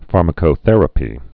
(färmə-kō-thĕrə-pē)